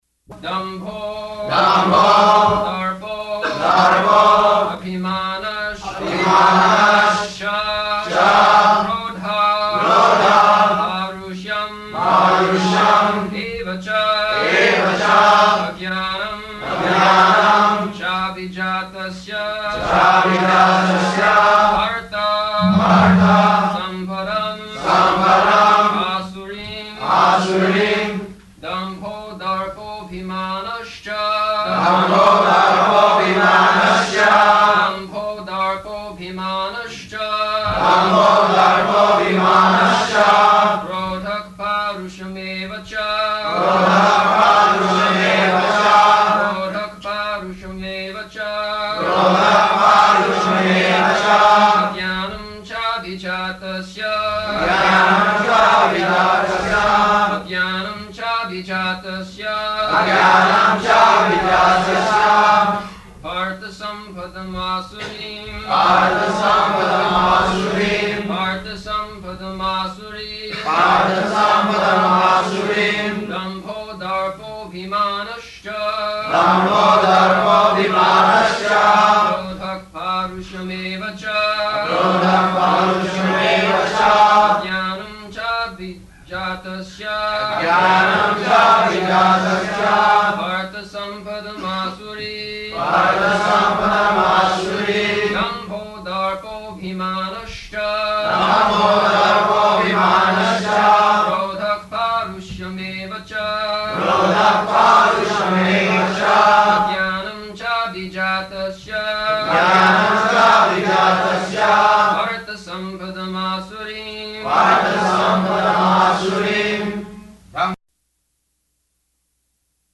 Type: Bhagavad-gita
Location: Honolulu
[tapping noise] [aside:] What is that?